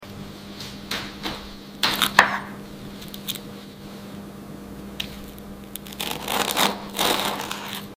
OH NO...kitten toast spread asmr